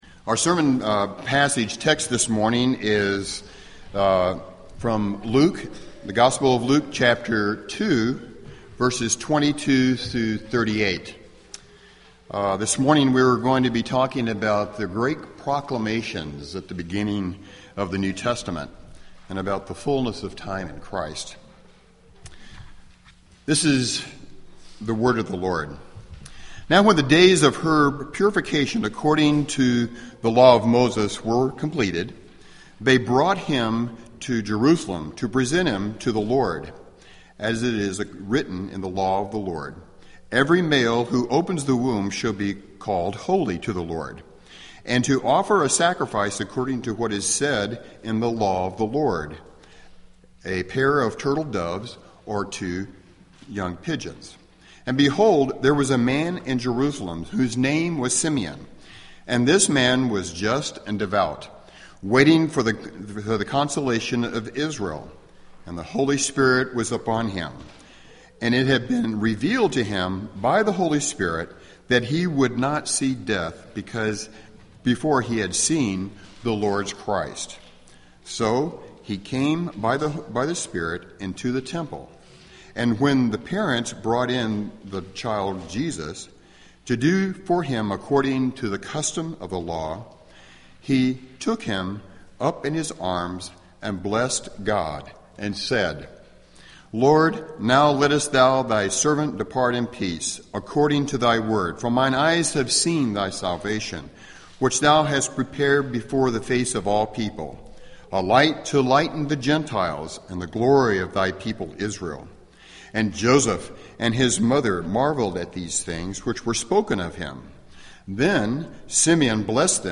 Sermons Mar 30 2014 Preached March 30